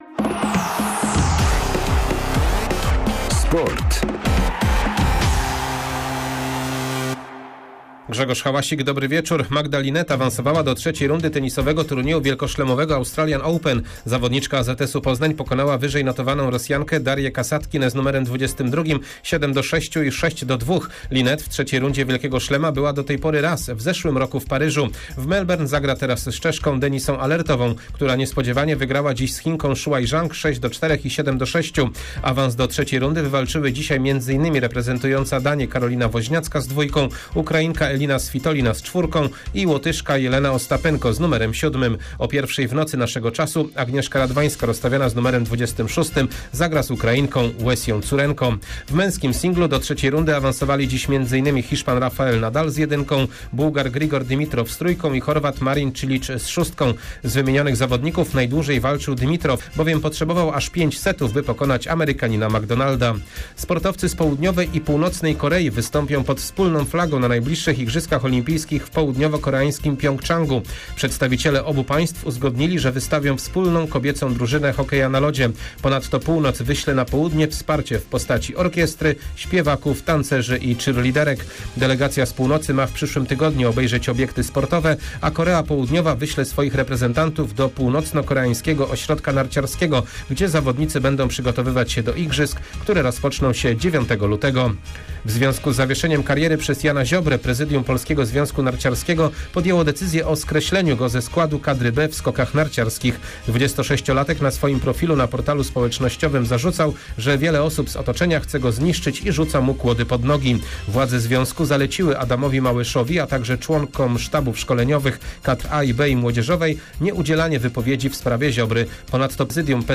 17.01 serwis sportowy godz. 19:05